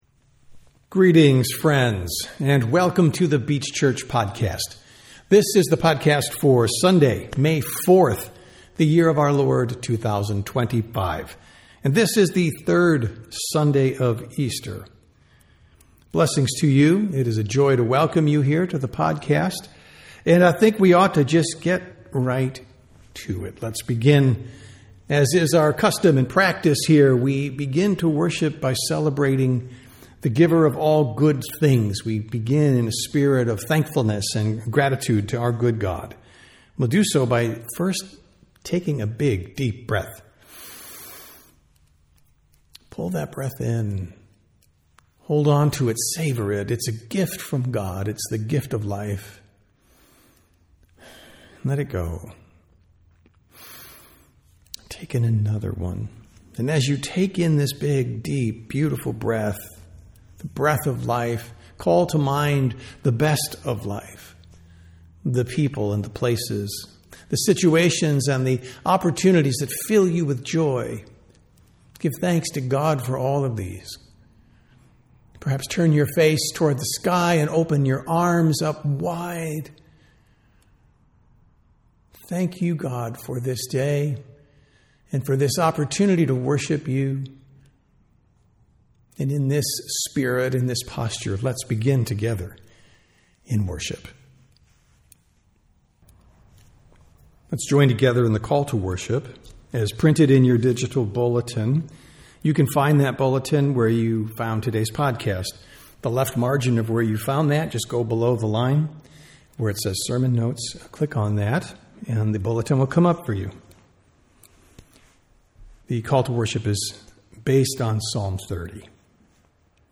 Sermons | The Beach Church
Sunday Worship - May 4, 2025